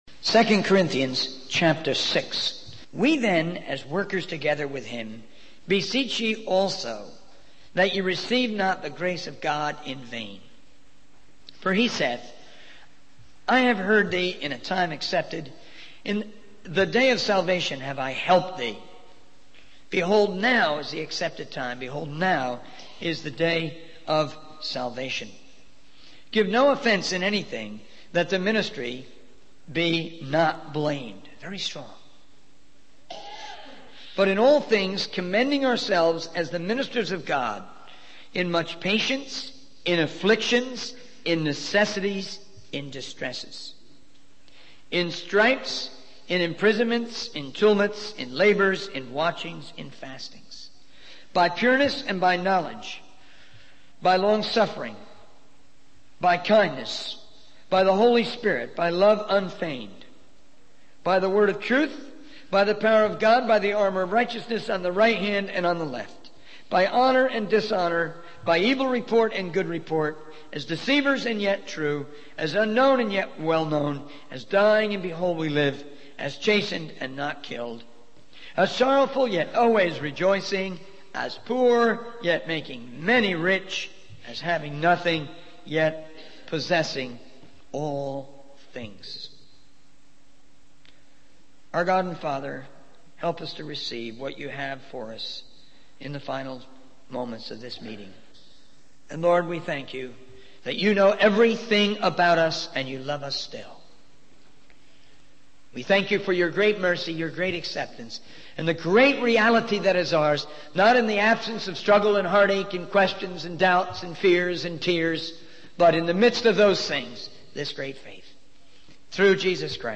In this sermon, the speaker focuses on verse 10 of the Bible, which talks about being sorrowful yet always rejoicing, poor yet making many rich, and having nothing yet possessing all things.